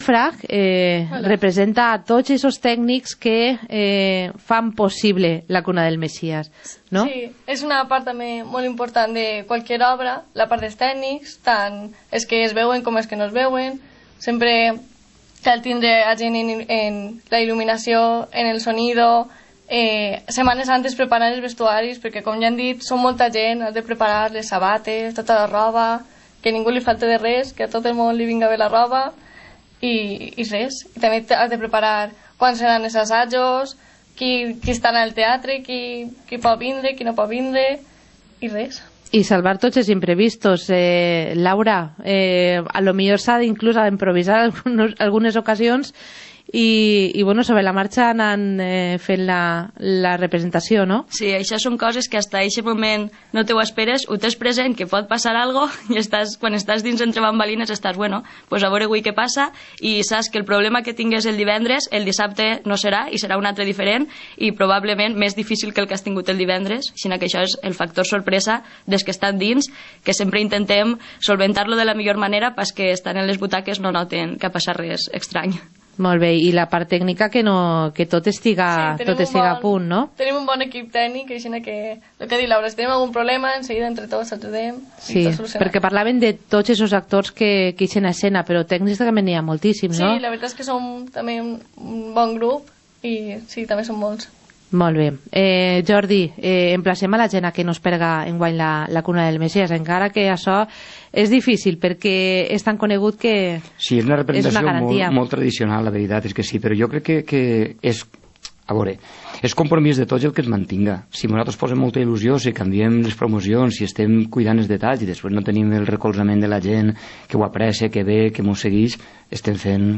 Informativo comarcal - martes, 13 de diciembre de 2016